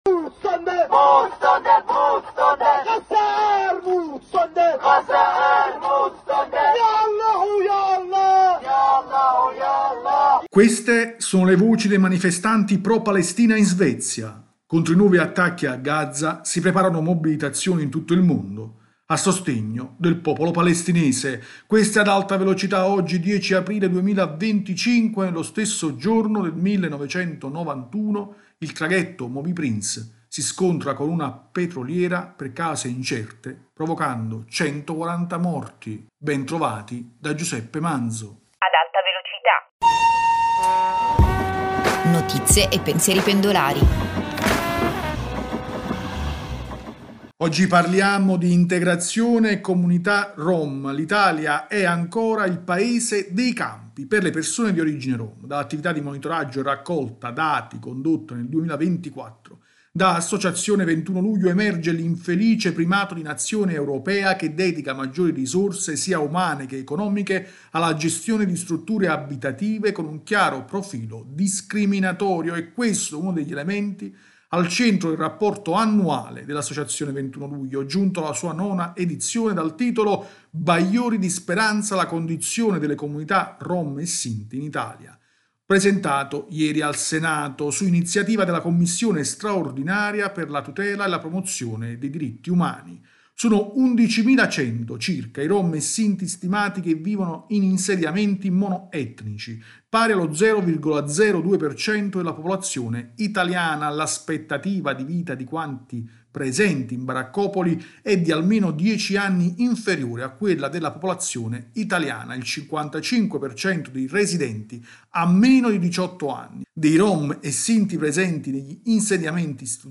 [Queste sono le voci dei manifestanti pro Palestina in Svezia: contro i nuovi attacchi a Gaza si preparano mobilitazioni in tutto il mondo a sostegno del popolo palestinese.